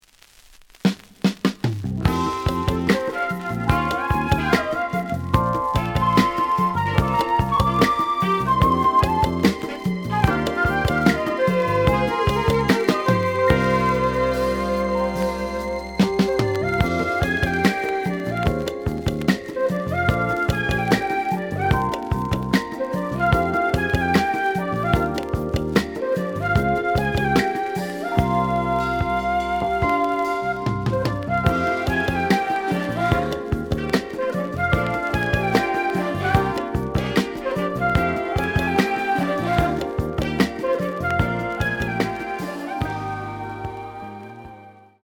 The audio sample is recorded from the actual item.
●Genre: Disco
Some noise on both sides.)